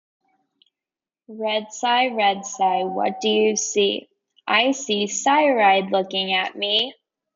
A repetitive, rhythmic chant follows Red Cy as it sees fresh white snow, elevator buttons, and Cy Ride, building to a final cumulative line.